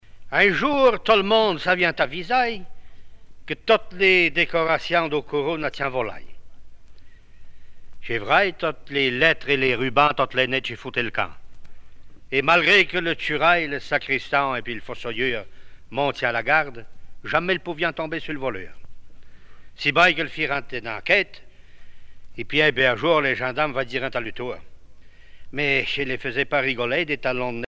sketch